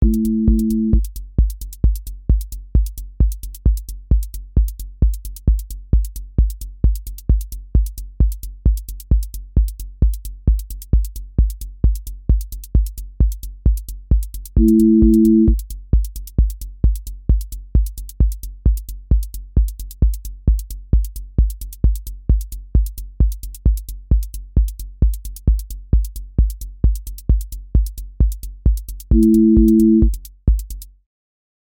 techno pressure with driven motion
• voice_kick_808
• voice_hat_rimshot
• voice_sub_pulse
• fx_drum_bus_glue
• tone_brittle_edge
• motion_drift_slow
Techno pressure with driven motion